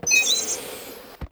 airprox.wav